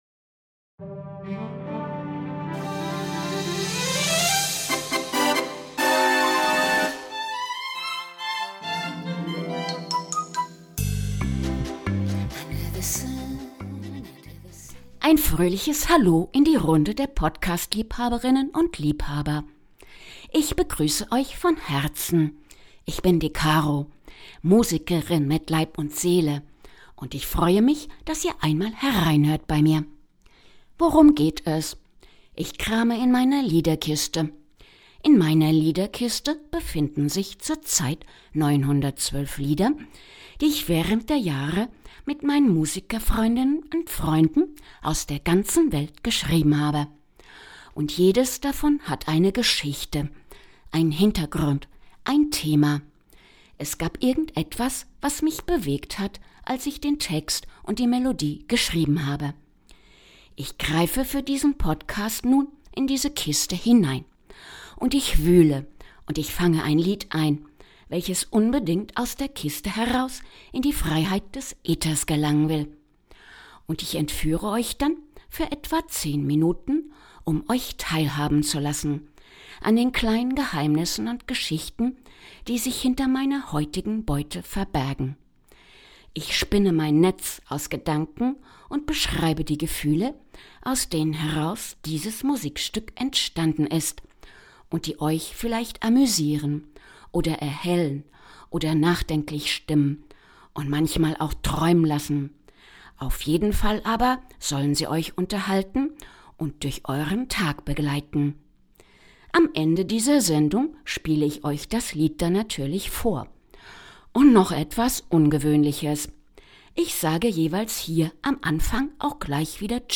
Eine akustische Popballade